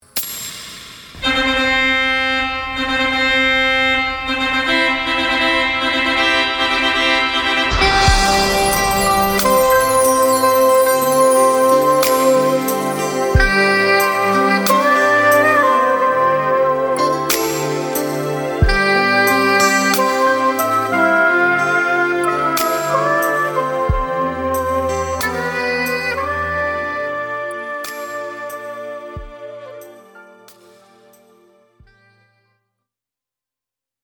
Gospel jazz